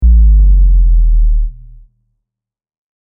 Low Buzz.mp3